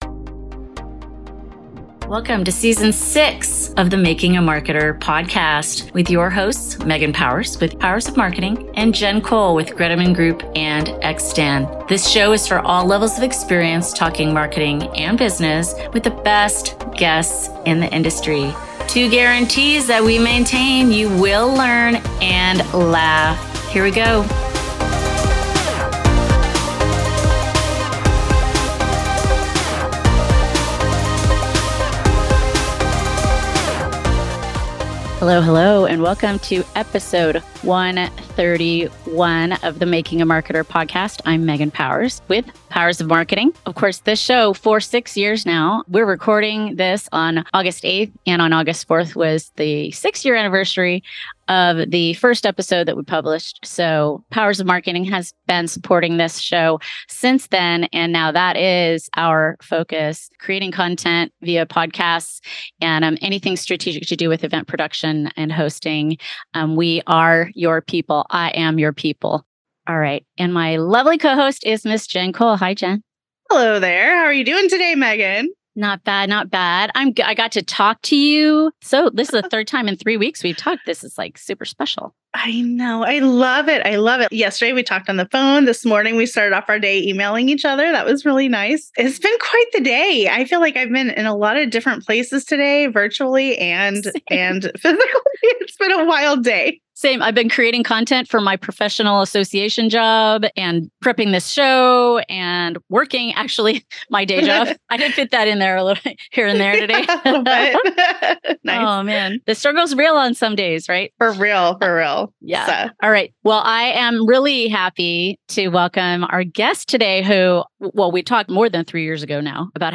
We had so much fun -- three marketers with the gift of gab makes for a fast-paced, insightful, and exciting conversation!